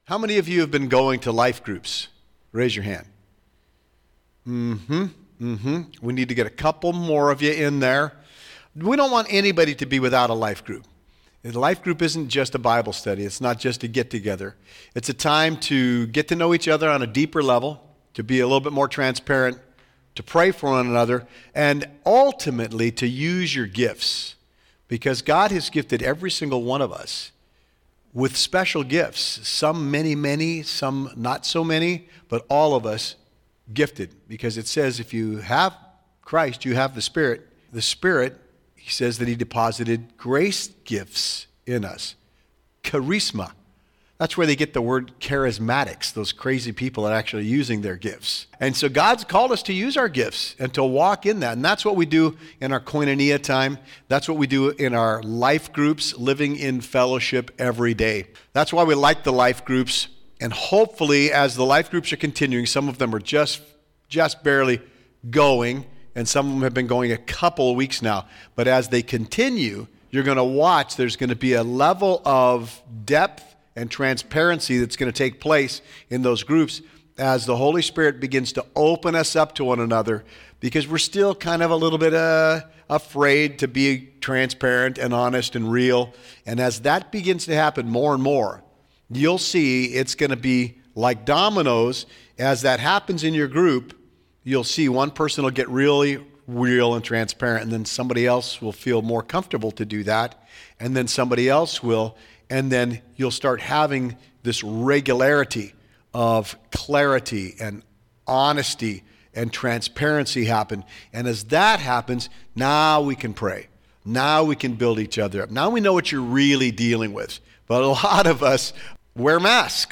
At 9:23 We have a wonderful KOINONIA time of open sharing about what we are seeing Jesus do among us. 25:05 We jump into Ephesians 3 starting with a recap of 1-9 - The Mystery - the inclusion of the Gentiles.
All Sermons